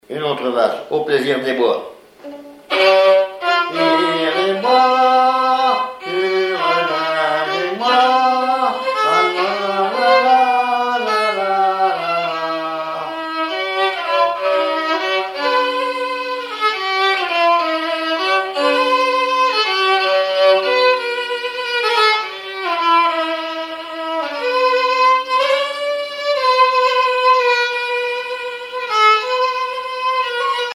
violoneux, violon
Flocellière (La)
danse : valse musette
Genre strophique